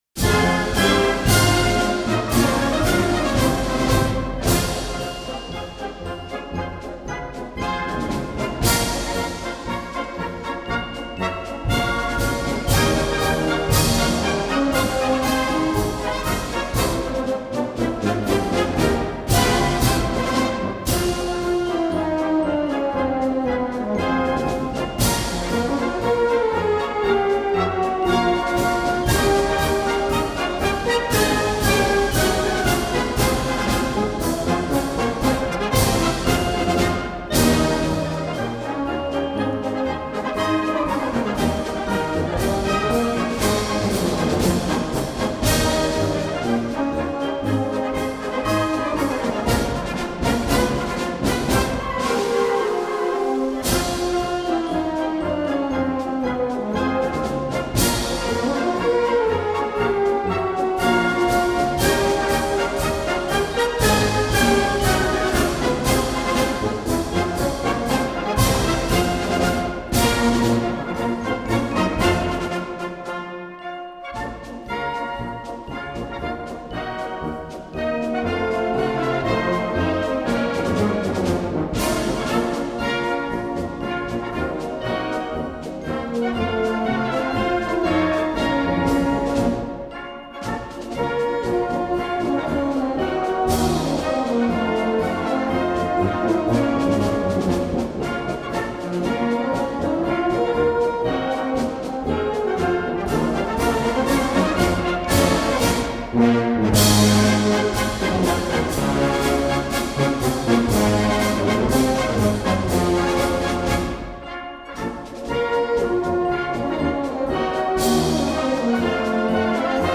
Марши
Современная запись, очень хорошее качество.